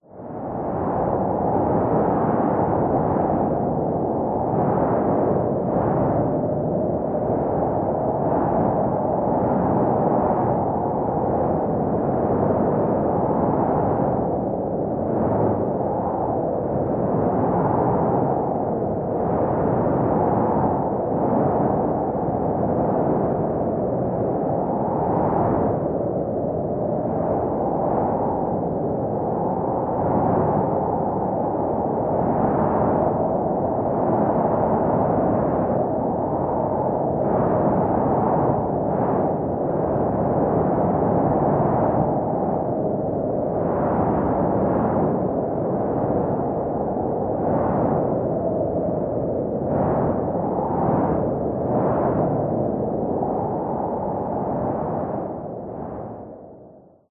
Звуки ветра
Имитация шторма в сказочных историях